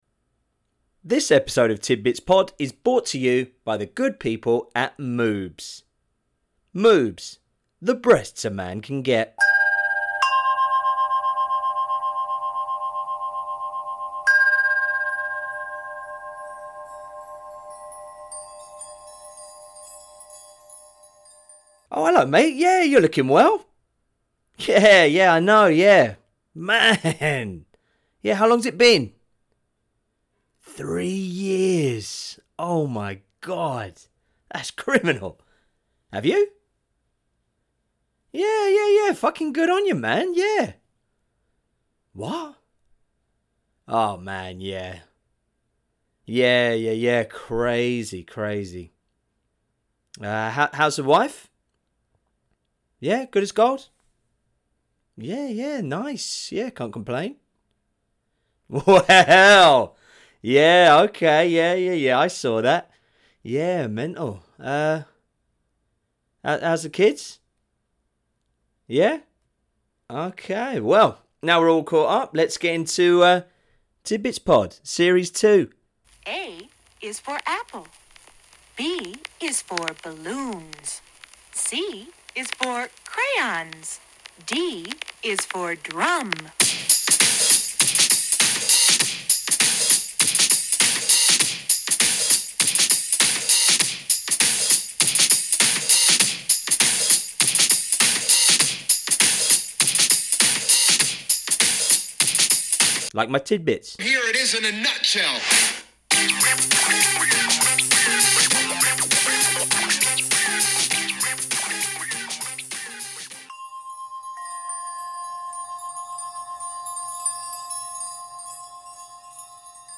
Recorded at home in Taichung, Taiwan. The purpose of this episode is just to launch the return.